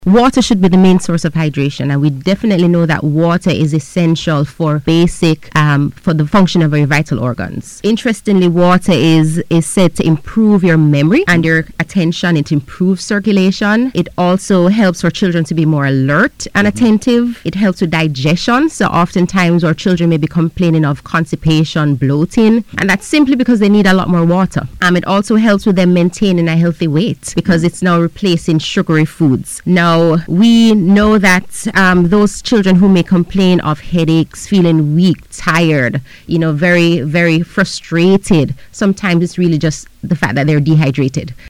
Speaking on NBC Radio